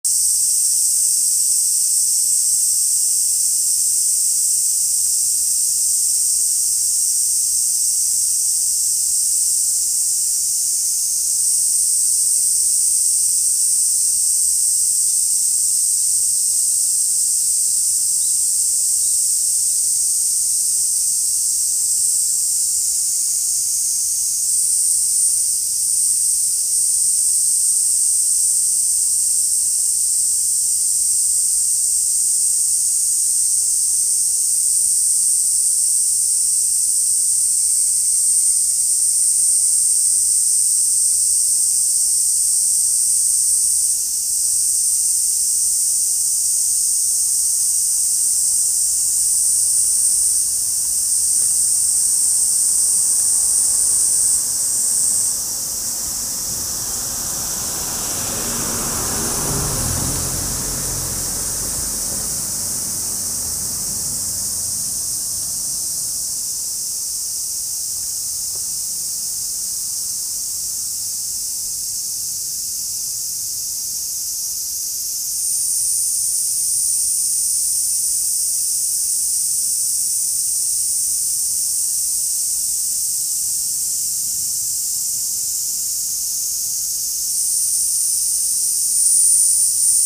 田舎の夏 環境音